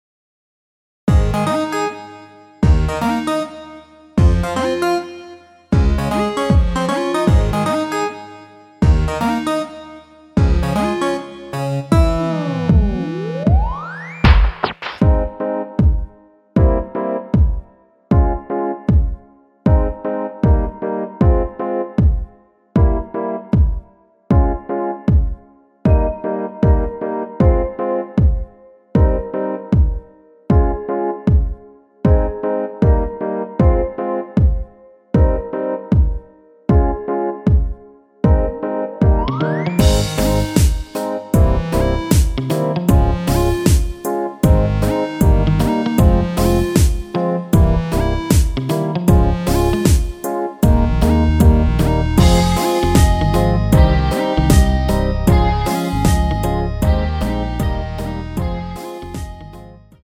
원키에서(-2)내린 MR입니다.
Ab
앞부분30초, 뒷부분30초씩 편집해서 올려 드리고 있습니다.